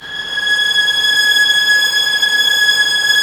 Index of /90_sSampleCDs/Roland L-CD702/VOL-1/STR_Vlns 6 mf-f/STR_Vls6 mf slo